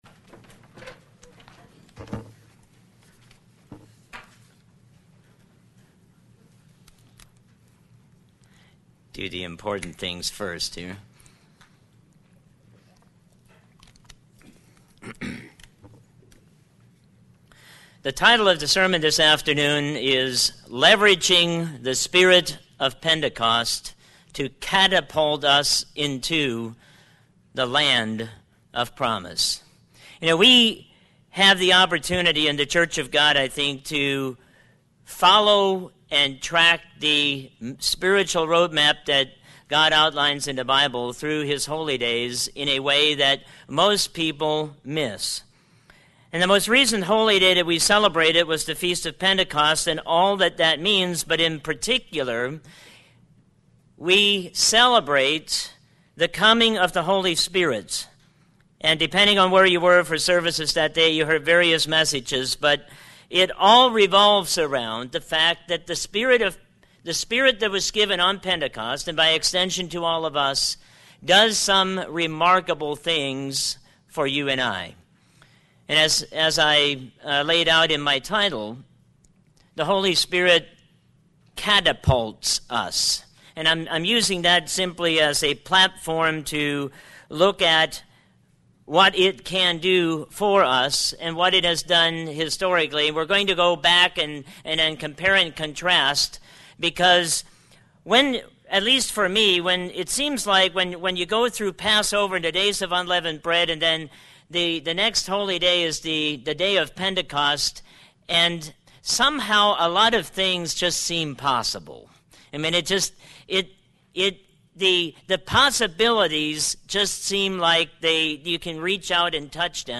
We celebrated the coming of the Holy Spirit given on Pentecost, noting along the way that it does some remarkable things for your and I. It catapults us spiritually forward. We look at what it can do for us and what it has done historically.
Sermons